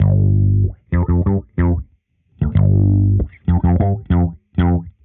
Index of /musicradar/dusty-funk-samples/Bass/95bpm